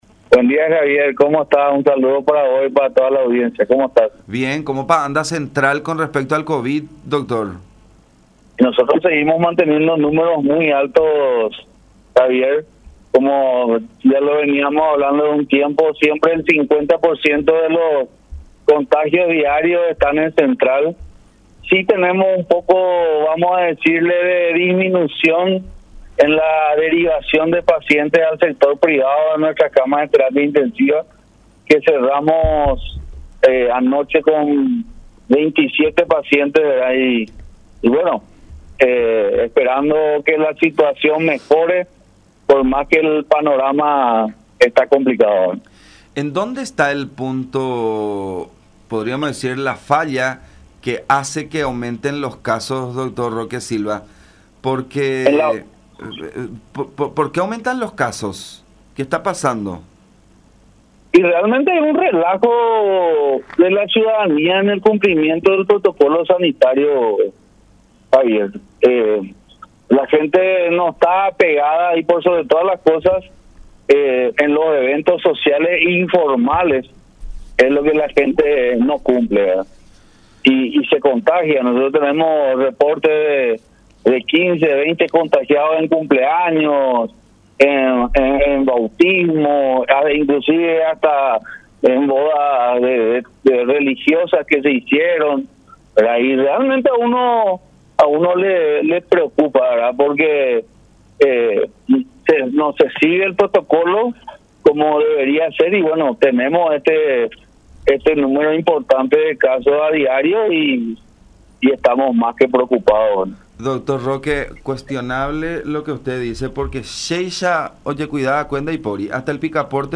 “Seguimos manteniendo los números muy altos en el Departamento Central. En los eventos sociales e informales se tienen los picos de más alto contagio porque no se siguen los protocolos. Llegamos a tener reportes de 15 a 20 contagiados en cumpleaños, así como en bodas religiosas y bautismos. Las personas hacen sus eventos en lugares cerrados y sin mucha ventilación” “, aseveró Silva en conversación con La Unión.